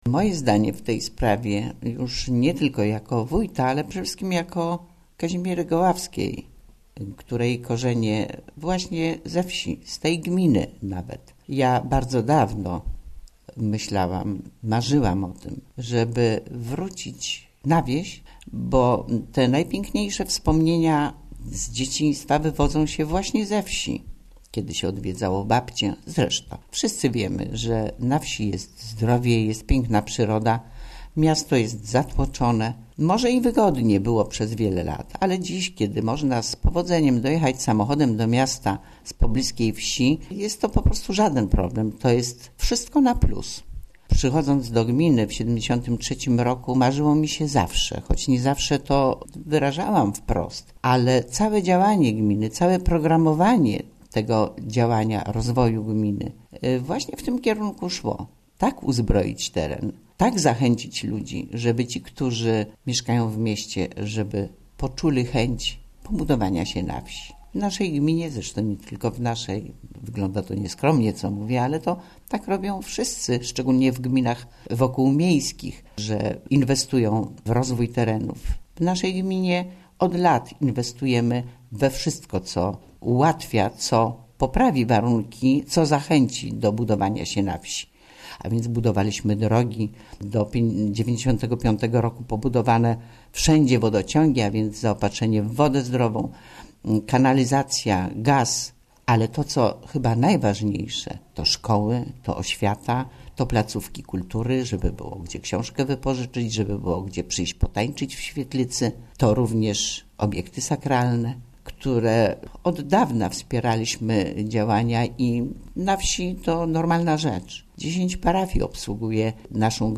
Wójt Gminy Łuków
Kazimiera Goławska